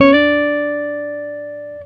Guitar Slid Octave 15-D3.wav